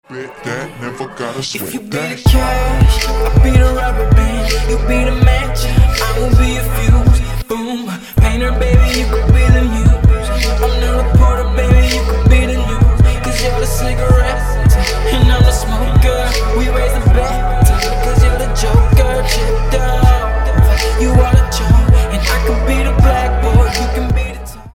• Качество: 320, Stereo
Мэшап